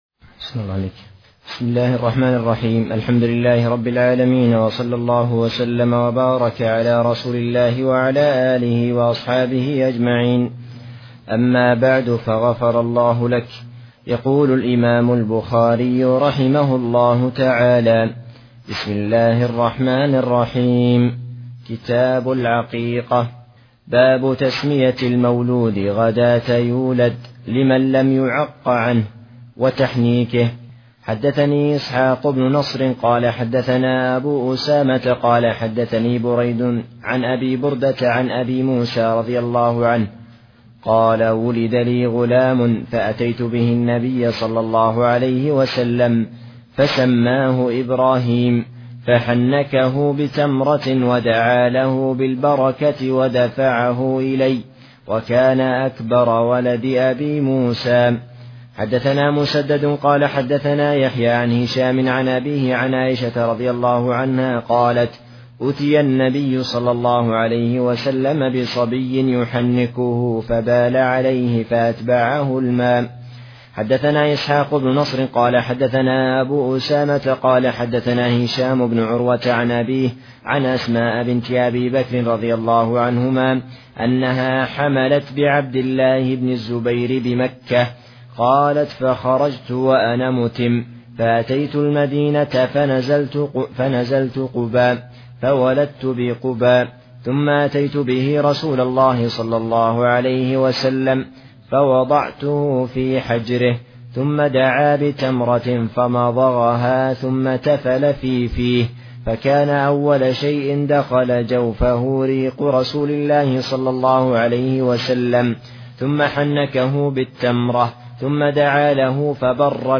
محاضرة صوتية نافعة، وفيها شرح الشيخ عبد العزيز بن عبد ا